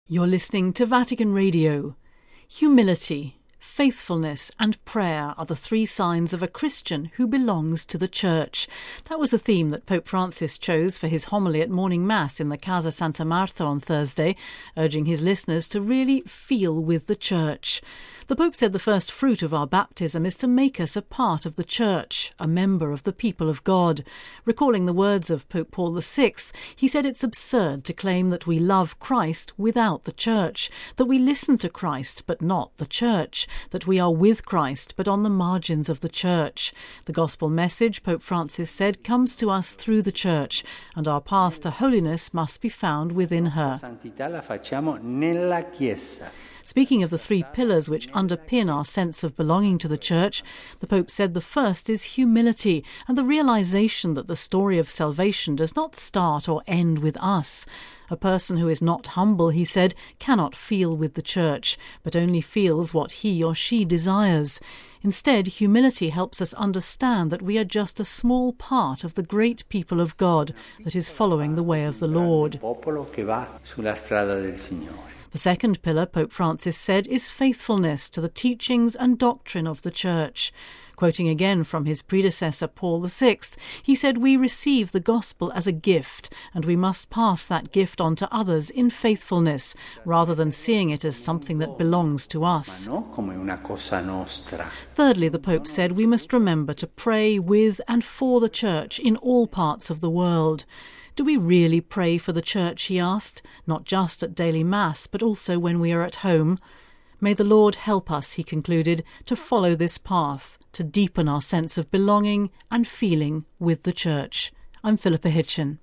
(Vatican Radio) Humility, faithfulness and prayer are the three signs of a Christian who belongs to the Church. That was the theme that Pope Francis chose for his homily at morning Mass in the Casa Santa Marta on Thursday, urging his listeners to really “feel with the Church”.